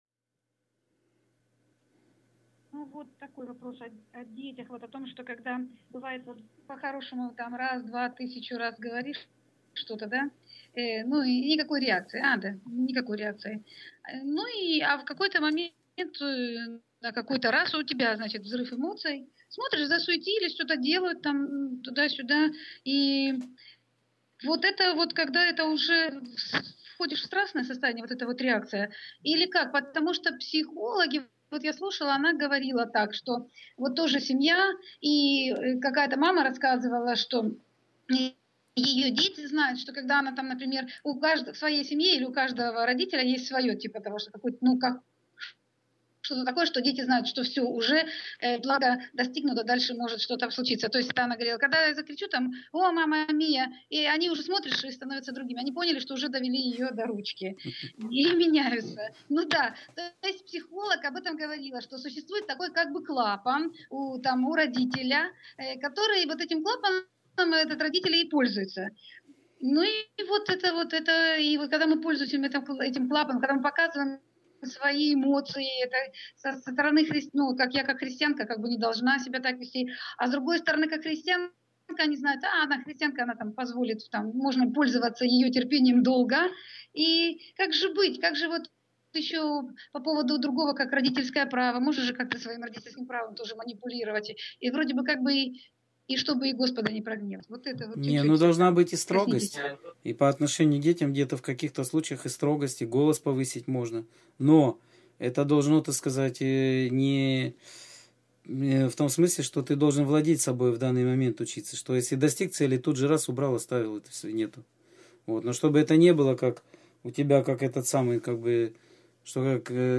Скайп-беседа 25.08.2013